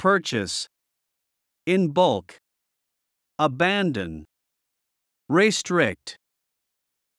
purchase /ˈpɜːrtʃəs/（動・名）購入する；購入
in bulk /bʌlk/（副）大量に、まとめて
abandon /əˈbændən/（動）放棄する、見捨てる
restrict /rɪˈstrɪkt/（動）制限する、限定する